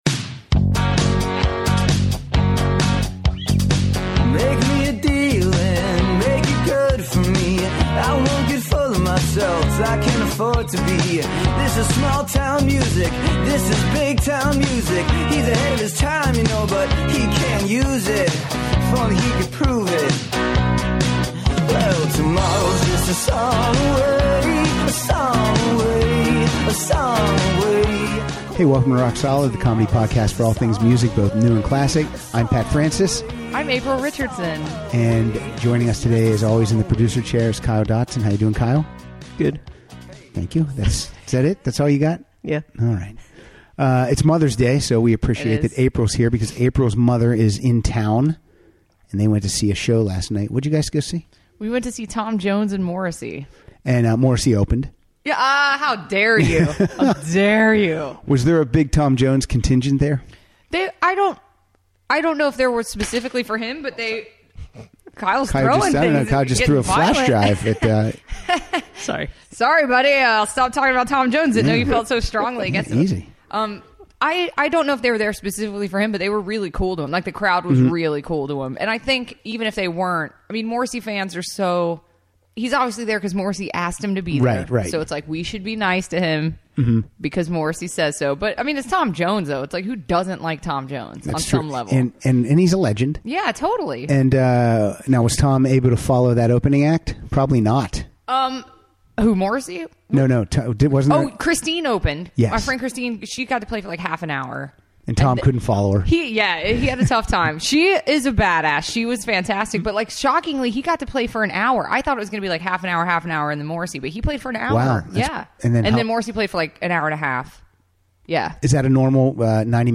play songs with loooooooong titles.